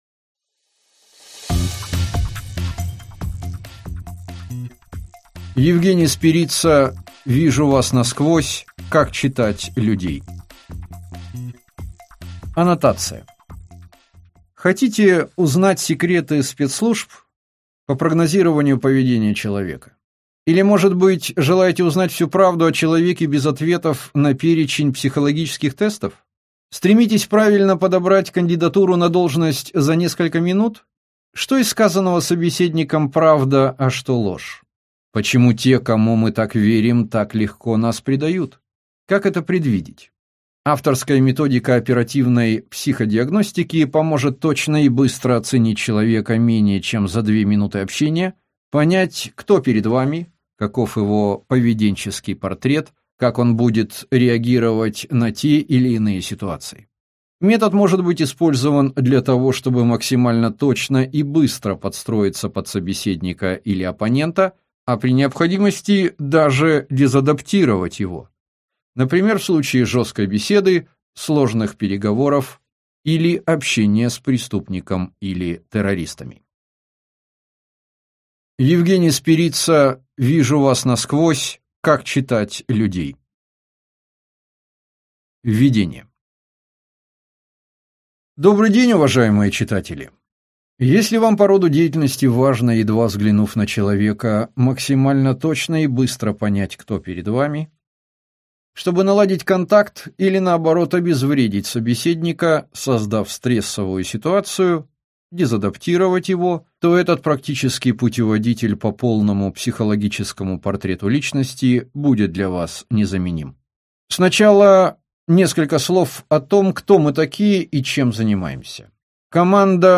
Аудиокнига Вижу вас насквозь. Как «читать» людей | Библиотека аудиокниг